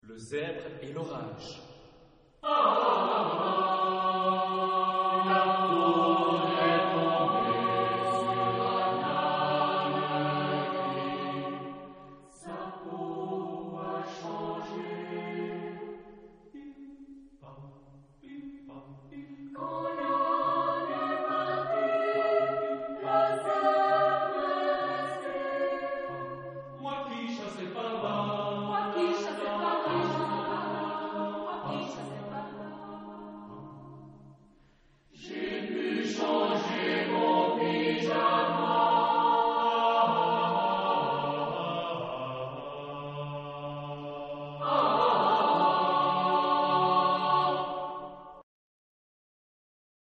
Caractère de la pièce : rythmé ; humoristique
Type de choeur : SATB  (4 voix mixtes )